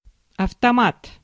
Ääntäminen
IPA : /ˈkiː.ɒsk/
IPA : /ˈki.ɑsk/